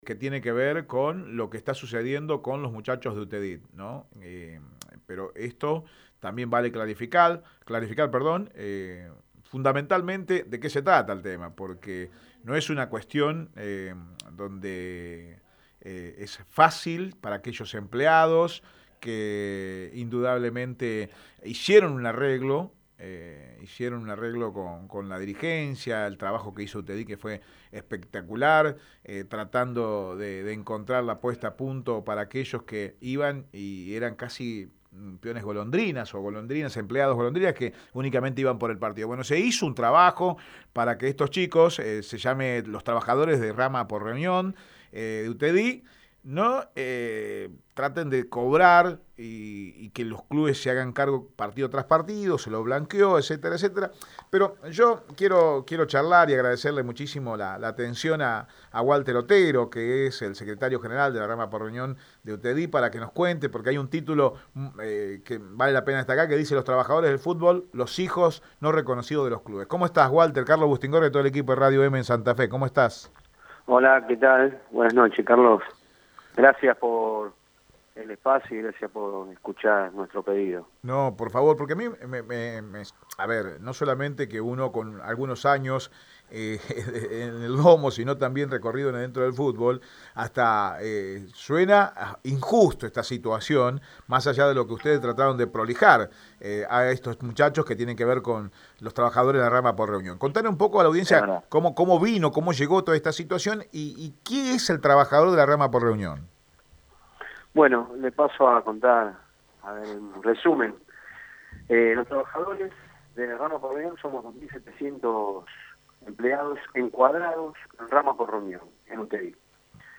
habló en exclusiva por Radio Eme deportivo por la negociación que llevan con AFA y los clubes por los 2700 empleados en riesgo por la crisis sanitaria.